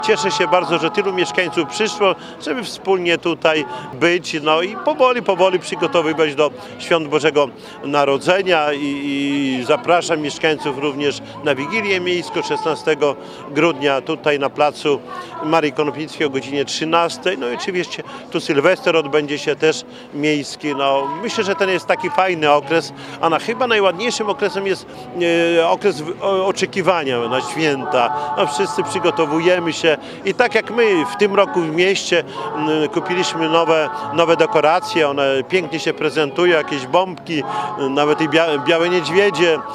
Jak mówi Czesław Renkiewicz, prezydent miasta, to szczególny czas oczekiwania na święta